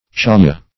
Chaja \Cha"ja\, n. [Native name.] (Zool.)